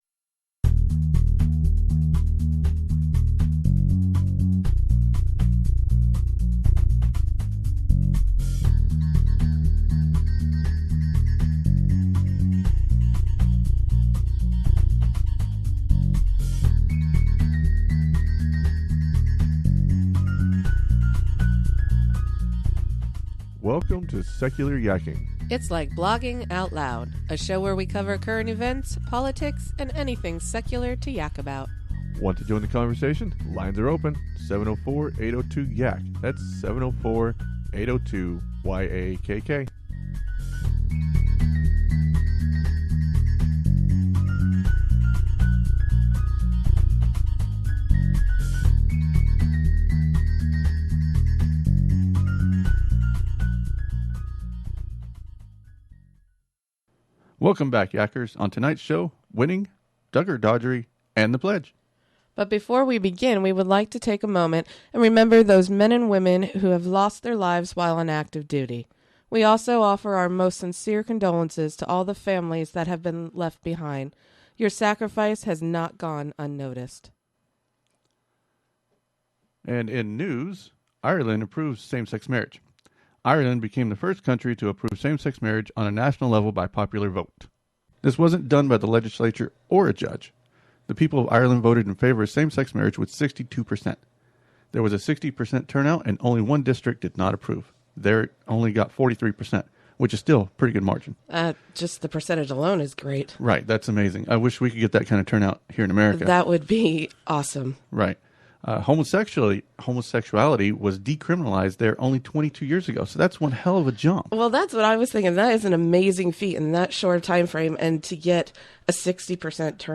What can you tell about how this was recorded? On this episode we cover Irish same sex marriage, Del Norte county(again), The Duggars and the Pledge. Sorry about the loss of part of the original audio. We suffered from connection issues. the dangers of living in the sticks.